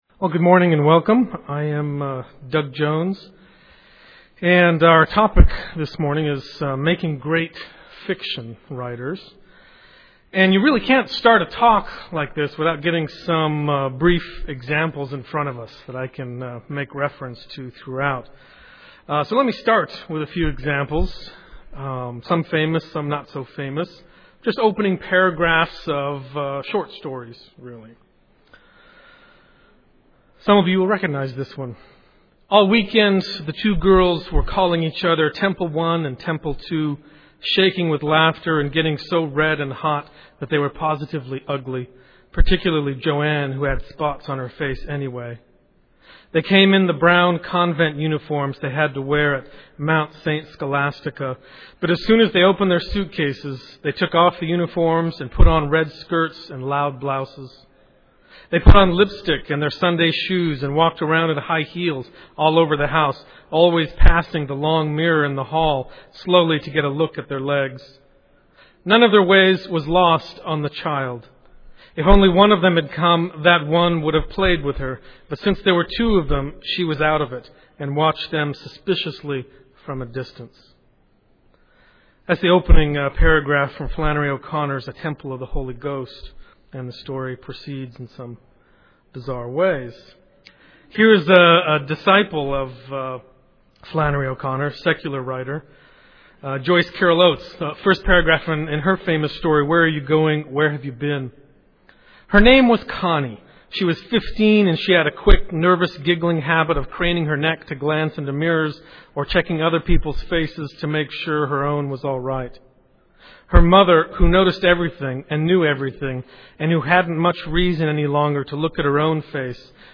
2007 Workshop Talk | 1:02:39 | 7-12, Rhetoric & Composition